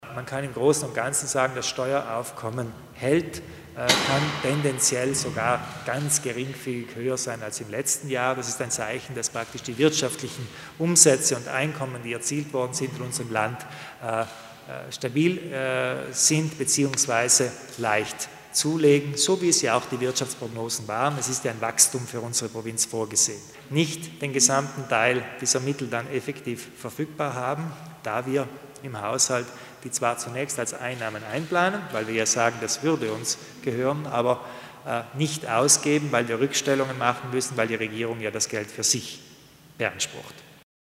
Landeshauptmann Kompatscher erläutert den Haushalt 2015